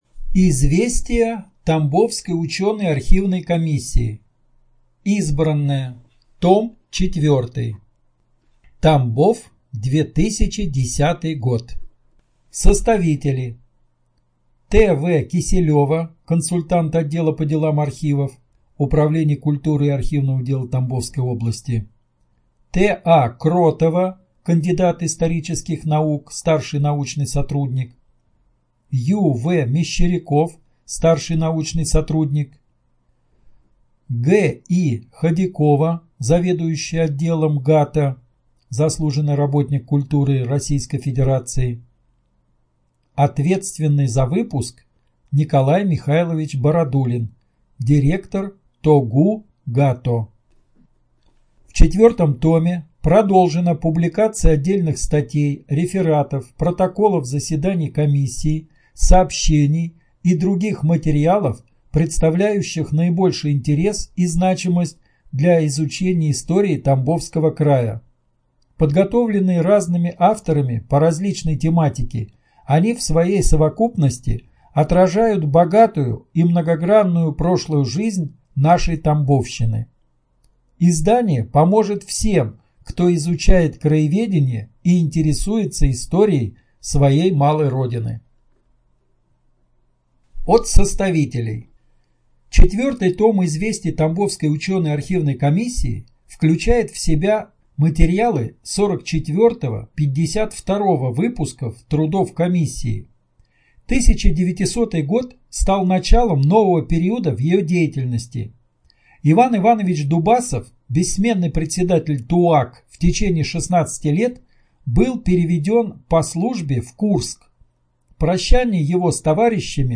Студия звукозаписиТамбовская областная библиотека имени А.С. Пушкина